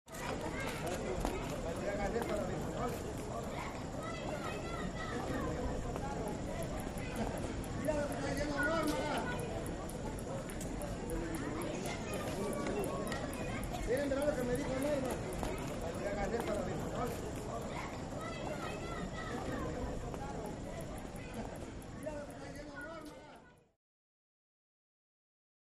Walla, Spanish | Sneak On The Lot
Medium Light Spanish Walla Outdoors